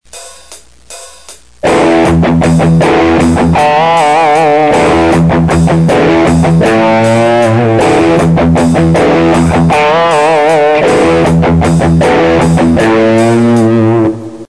Для примера: после вступления темп "Into the Void" немного ускоряется [1:14], я играю риф, приведенный в ПРИМЕРЕ 2, затем вступает голос [1:41], и во второй раз риф у меня звучит уже немного иначе.